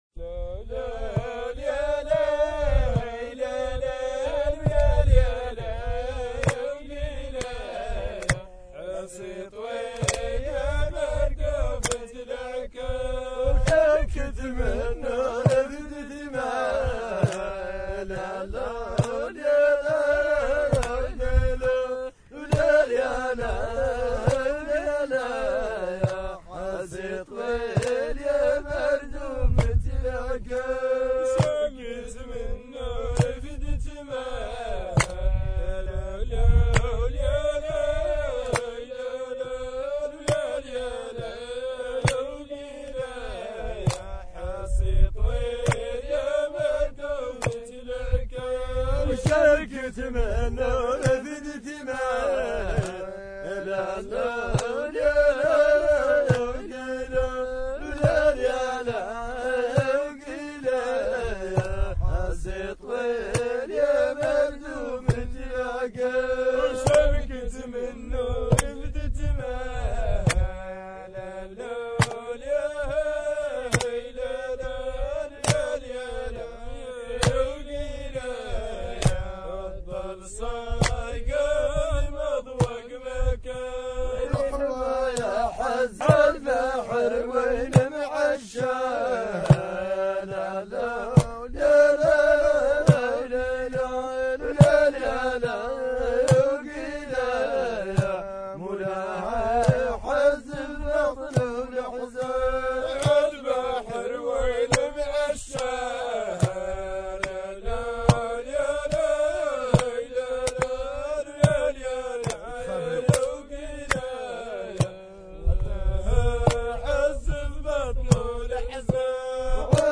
Chants traditionnels sahraouis
1 - Rythme GUEDRA